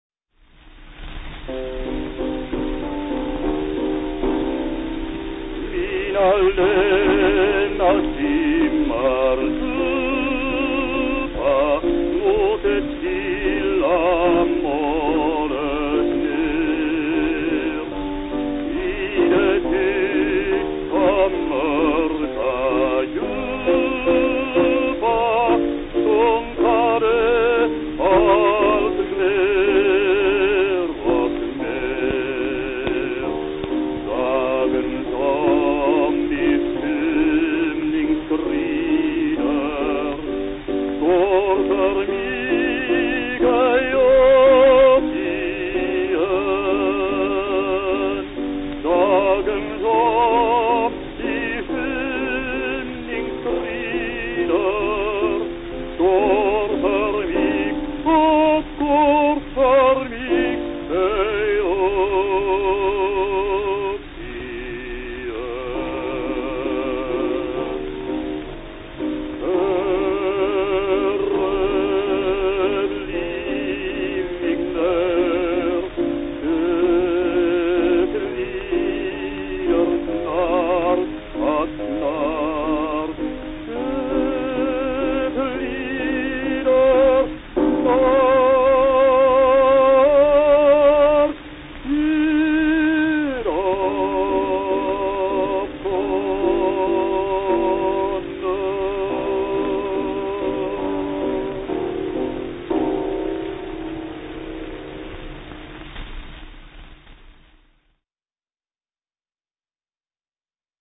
Schellack �r det s�msta lagringsmediet.